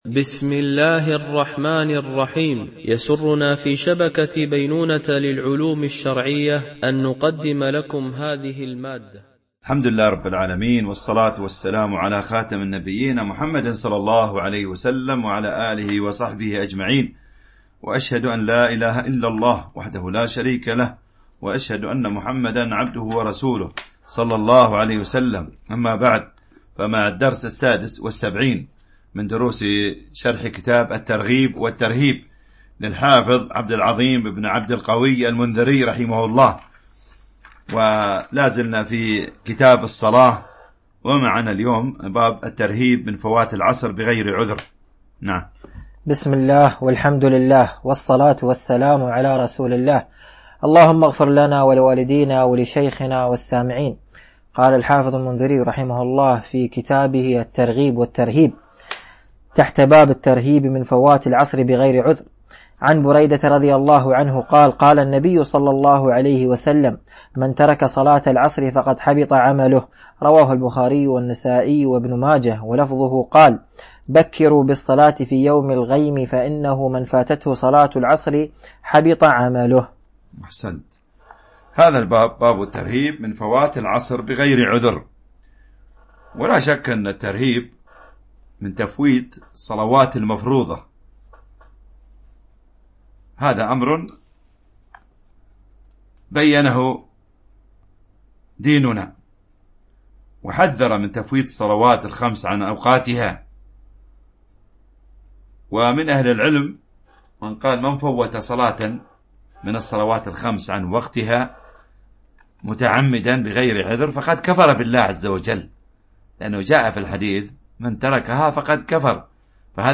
الدرس 76 ( كتاب الصلاة .الحديث 690 - 693
MP3 Mono 22kHz 32Kbps (CBR)